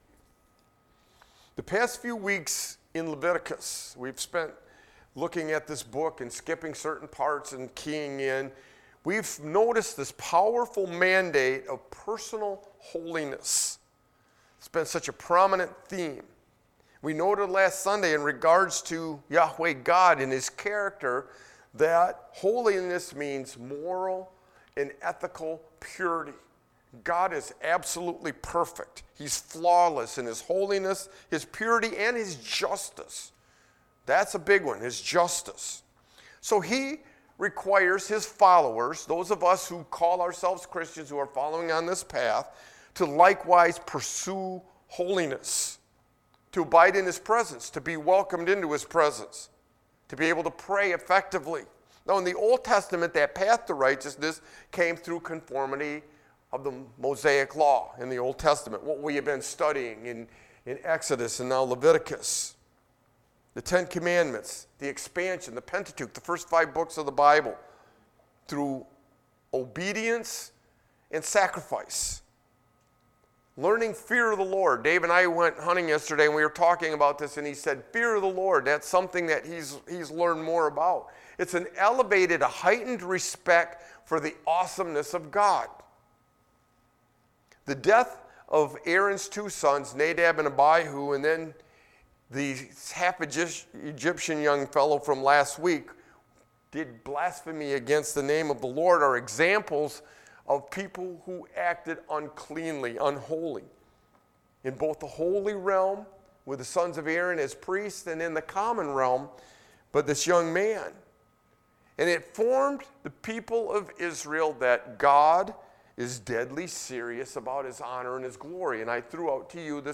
Sermon-Looking-Forward-from-an-OT-View-XIV.mp3